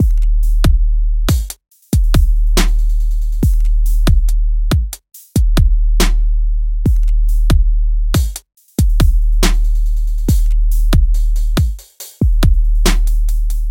旧学校 鼓循环10
Tag: 70 bpm Hip Hop Loops Drum Loops 2.31 MB wav Key : Unknown